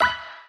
Appear_Scatter_Sound.mp3